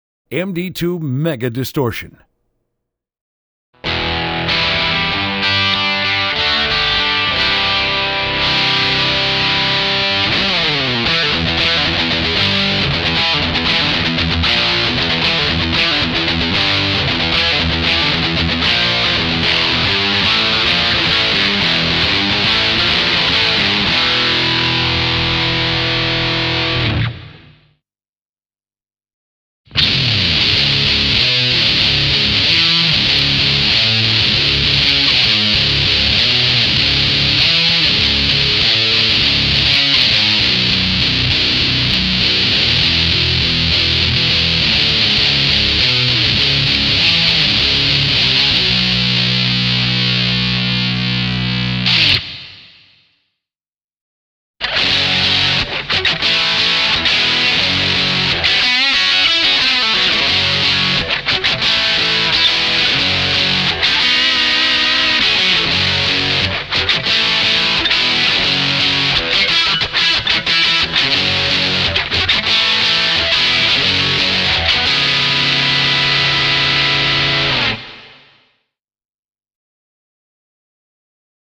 MD-2 Tone Demo